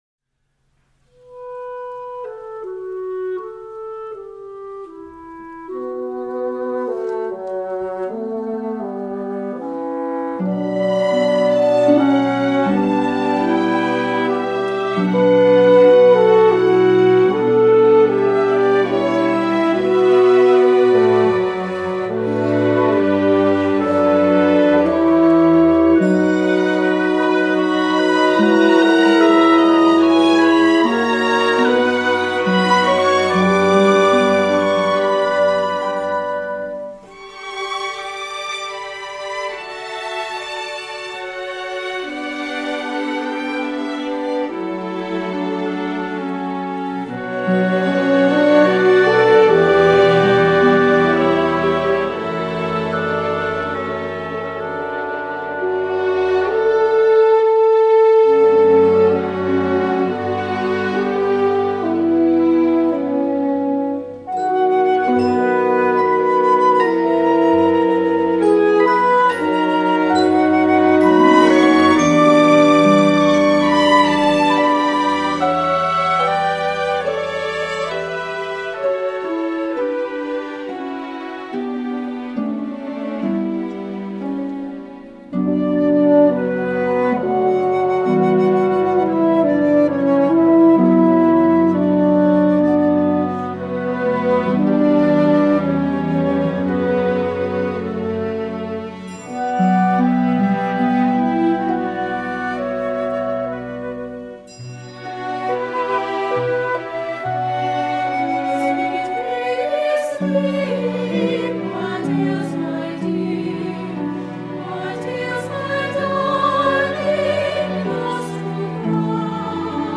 CHAMBER Orchestra & Chorus
This is not your typical “lullaby”!
WOODWINDS:  1 Flute, 1 Oboe, 1 Clarinet in Bb, 1 Bassoon
BRASS:  1 Horn
HARP:  1 Harp